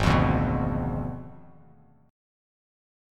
Abdim7 chord